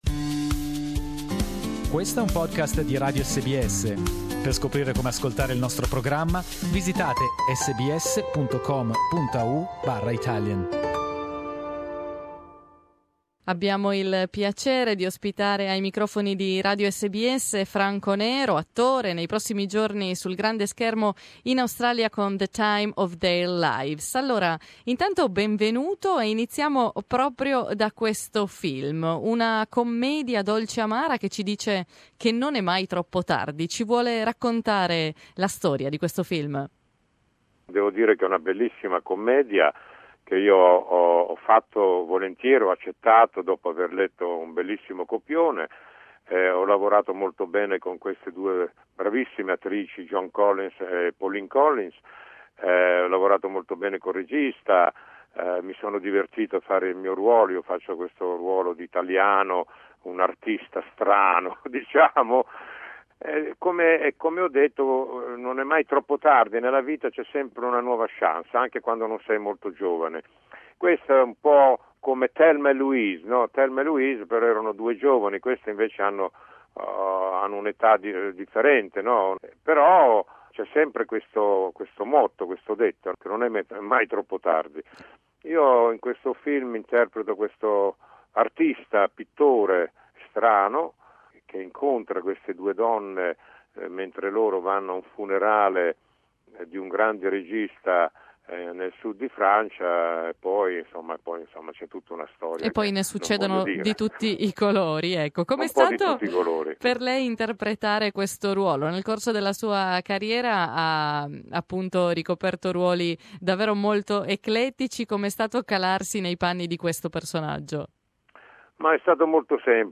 The Italian acting legend Franco Nero is back with a movie that says that it's never too late. We talked with him about this funny comedy and his long and successful career.